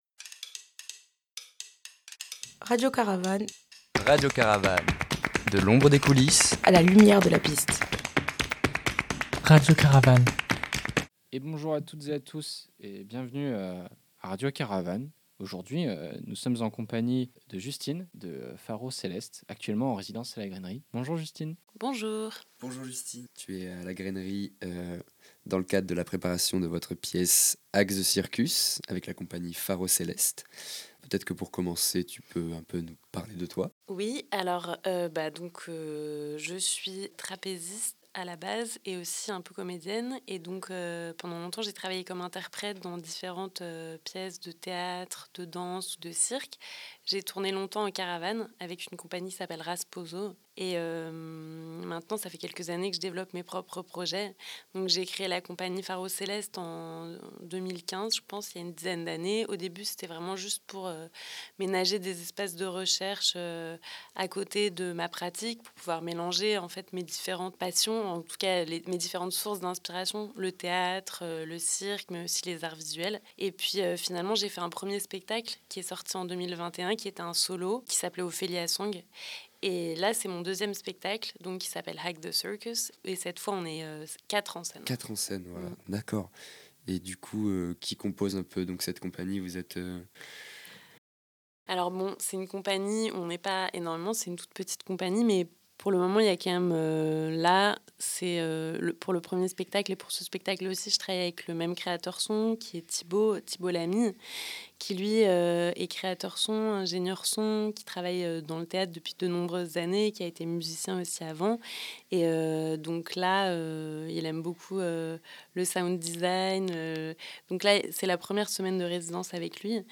RC_2025_mars_itw Faro Celeste.mp3